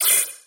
Звуки скроллинга
Звук прокрутки для игрового интерфейса